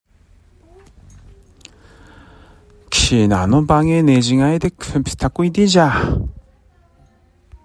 津軽方言：古代の発音
津軽方言のを聞くと、古代日本語の音声の一部が、現代の津軽方言に受け継がれていることが分かります。